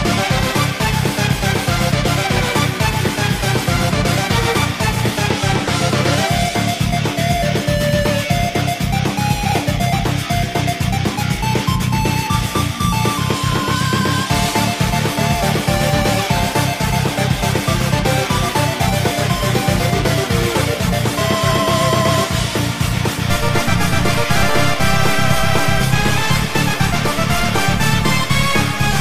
• Качество: 128, Stereo
громкие
веселые
саундтреки
без слов
Electronica